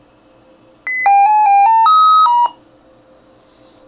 p52-startup-beep.mp3